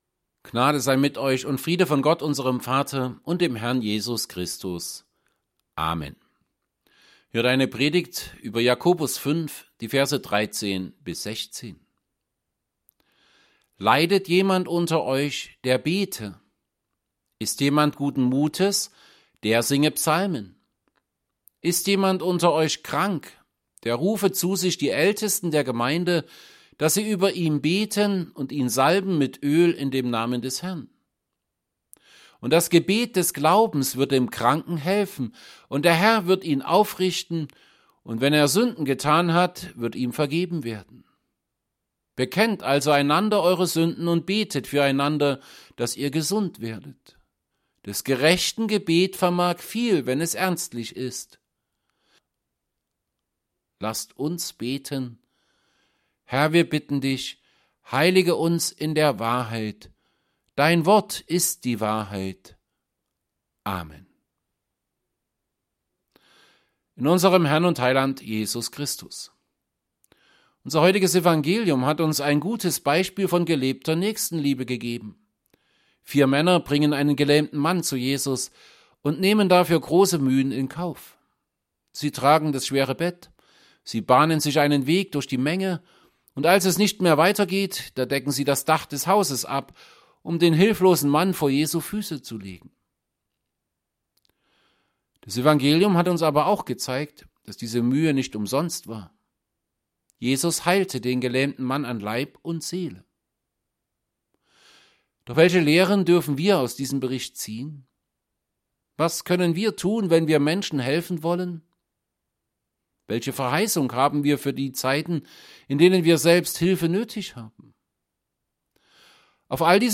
James 5:13-16 Gottesdienst: Gottesdienst %todo_render% Dateien zum Herunterladen Notizen « 18.
Predigt_zu_Jakobus_5_13b16.mp3